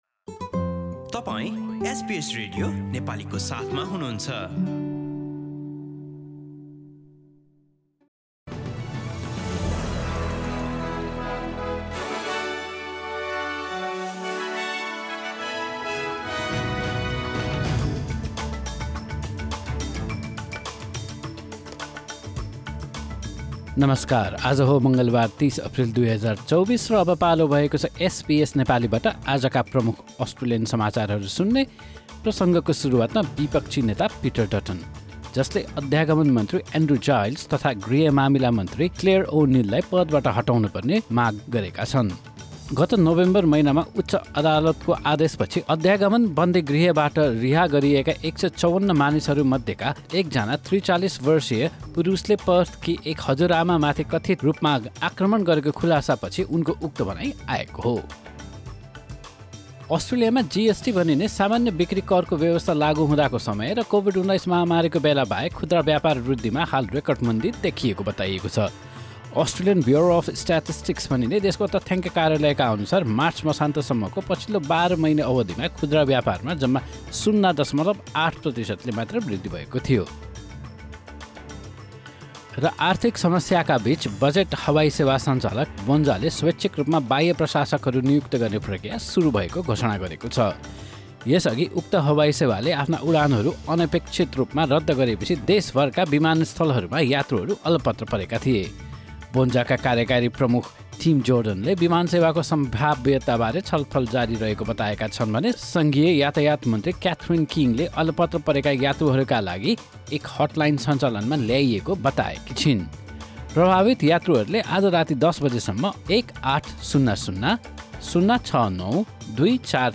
SBS Nepali Australian News Headlines: Tuesday, 30 April 2024